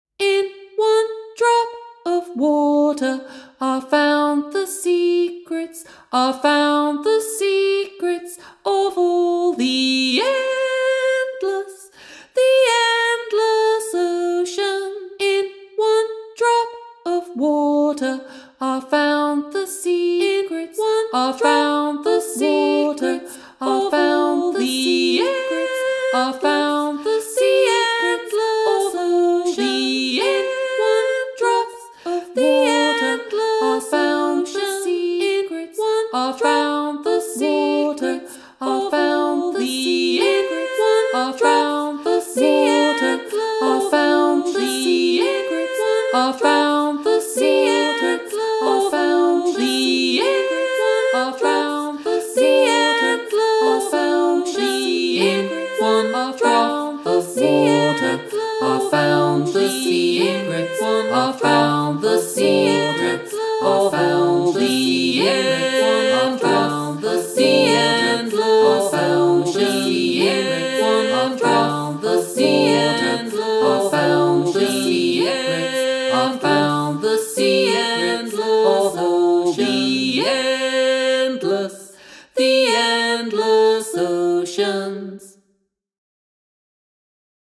Rounds and Canons